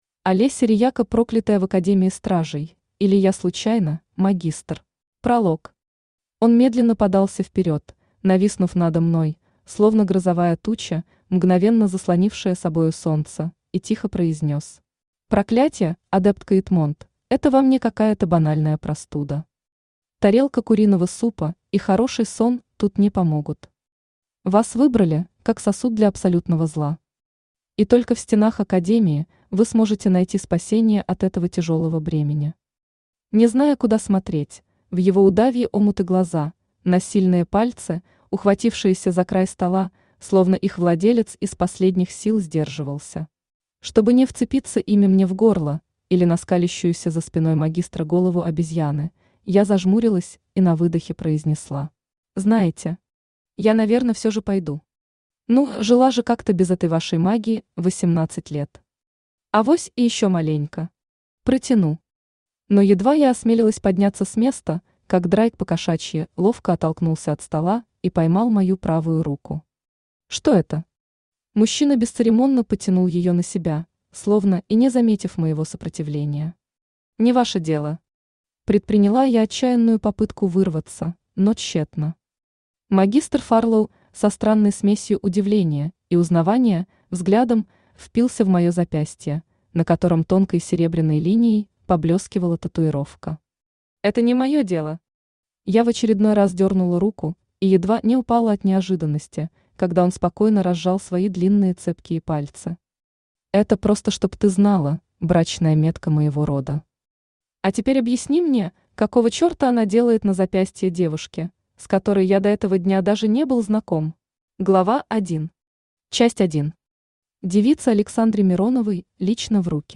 Аудиокнига Проклятая в Академии Стражей, или Я случайно, магистр!
Автор Олеся Рияко Читает аудиокнигу Авточтец ЛитРес.